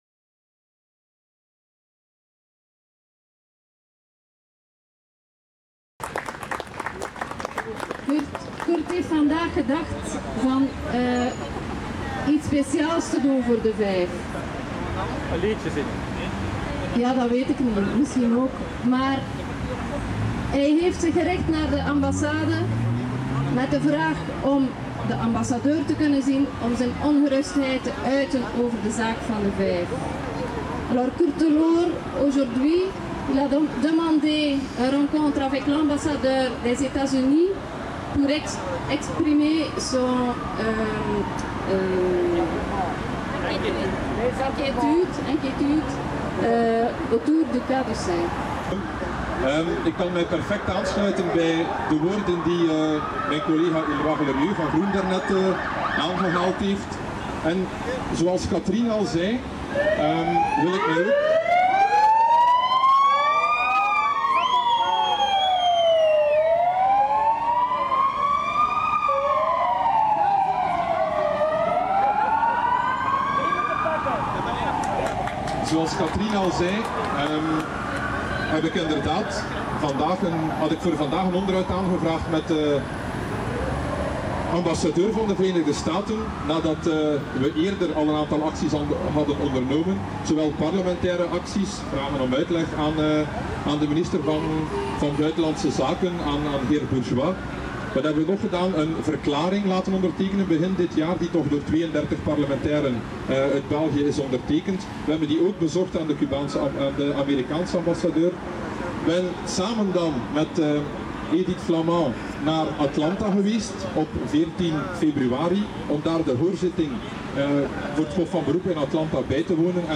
En face de l’ambassade de Etats Unis se sont réuni des organisations de soutiens au peuple cubain pour exiger la libération des cinq héros qui purgent une peine injuste dans les prisons de EEUU.